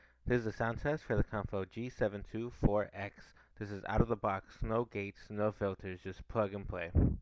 Sound Test